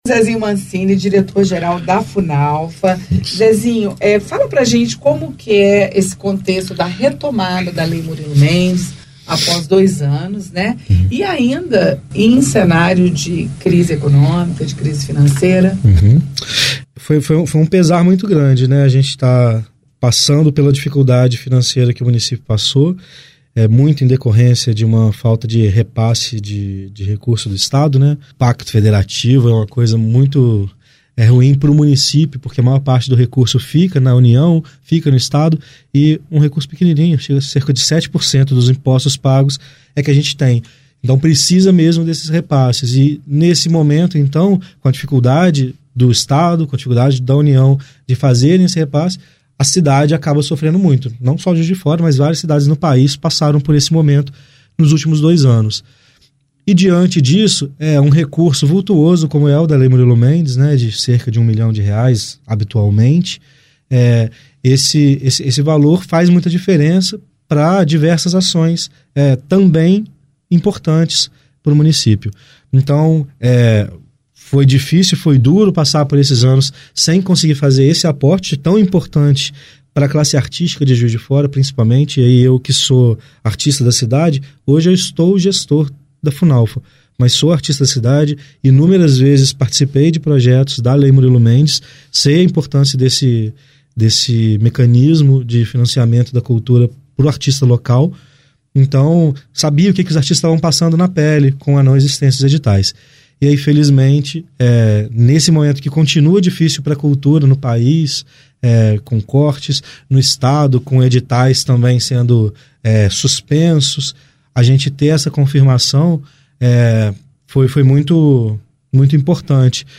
Entrevista na íntegra com o diretor geral da Funalfa, Zezinho Mancini.